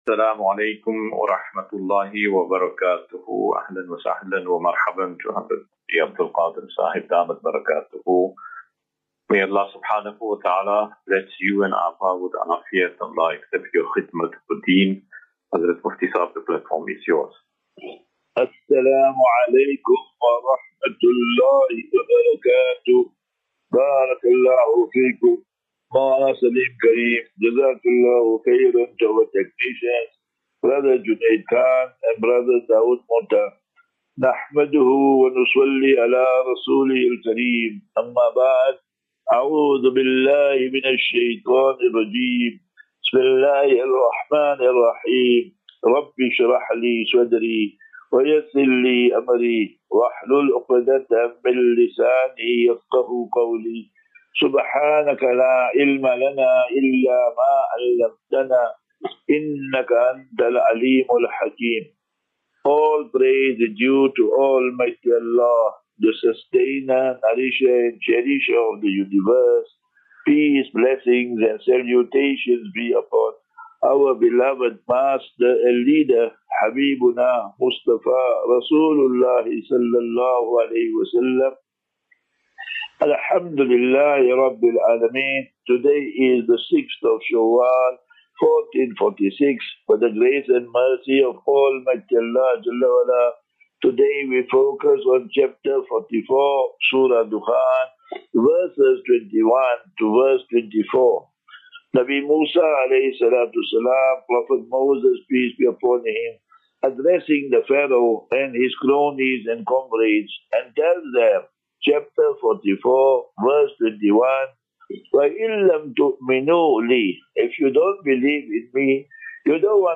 5 Apr 05 March 2025 Assafinatu - Illal - Jannah. QnA